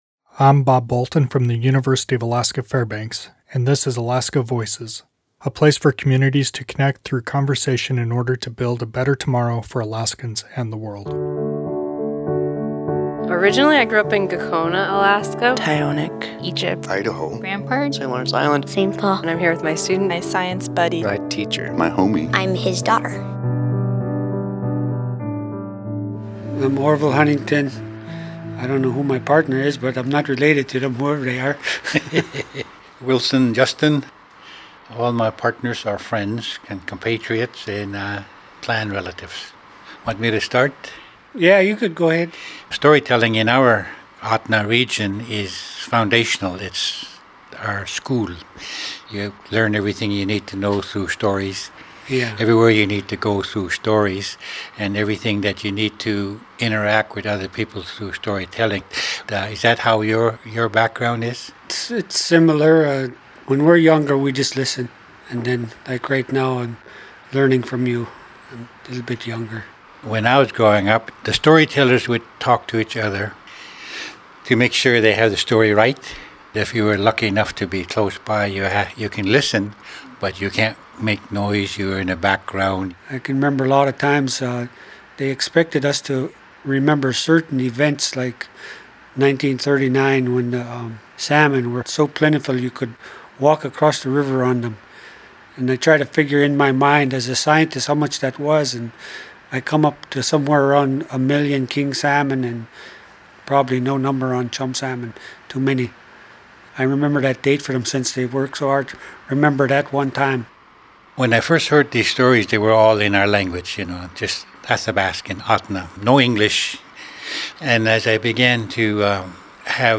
This interview was recorded in collaboration with StoryCorps.
Music: "Arctic Evening" by Marcel du Preez